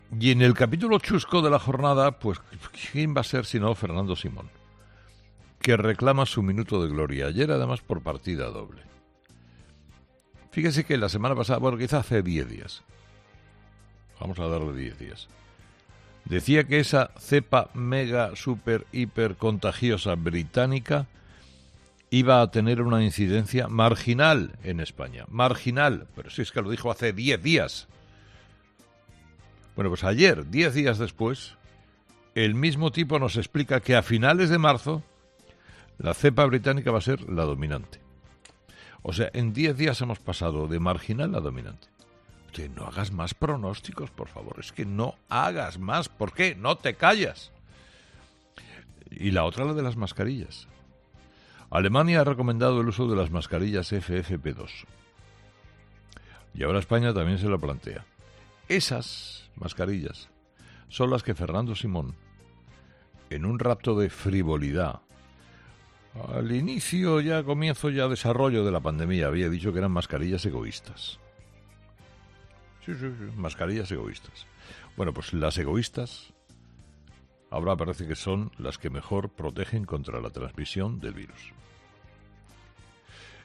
En su monólogo de este viernes, Carlos Herrera ha pronunciado estas palabras dedicadas a Fernando Simón, y de paso, a la nefasta gestión de la pandemia por parte del Gobierno: